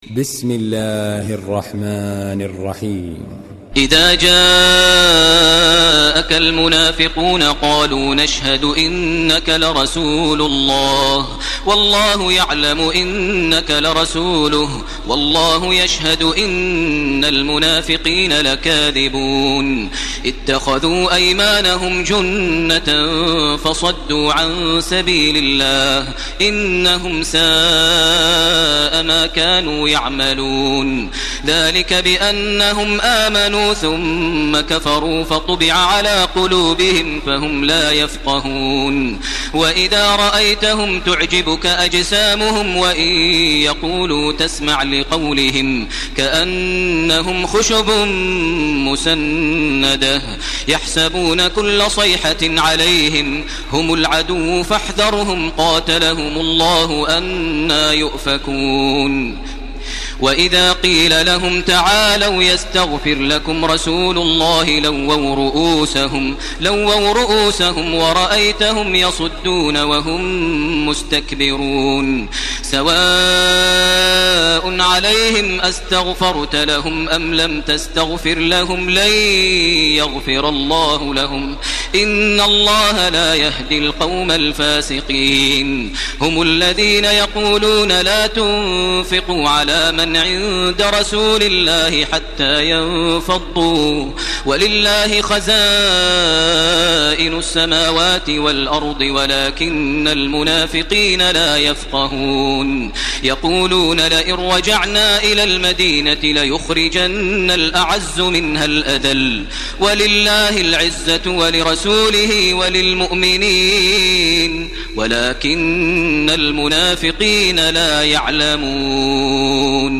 سورة المنافقون MP3 بصوت تراويح الحرم المكي 1431 برواية حفص عن عاصم، استمع وحمّل التلاوة كاملة بصيغة MP3 عبر روابط مباشرة وسريعة على الجوال، مع إمكانية التحميل بجودات متعددة.
تحميل سورة المنافقون بصوت تراويح الحرم المكي 1431